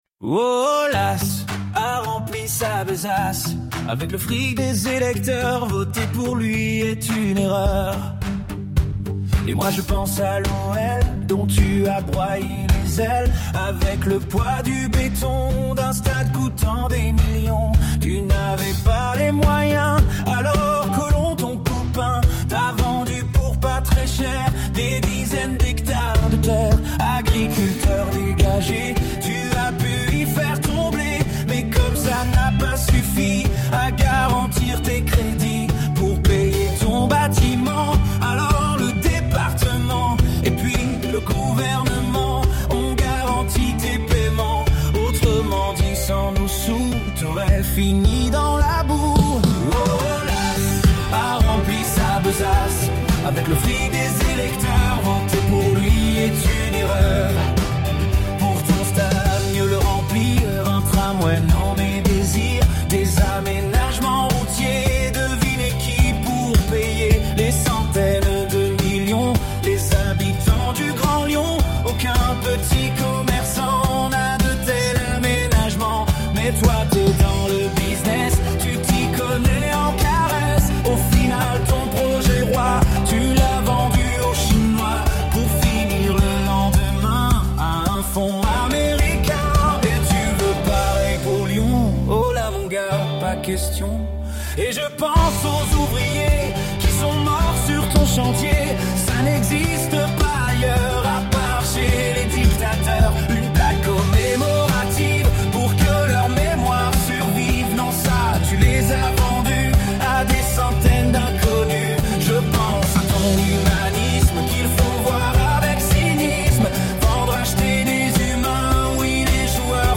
le site de la chanson humoristique